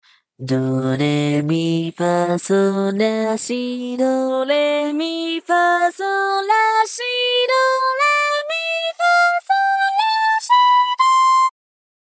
性別：女
VOICEBANK
收錄音階：A3 B3 D4 F4 G4 D5